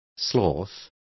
Complete with pronunciation of the translation of sloth.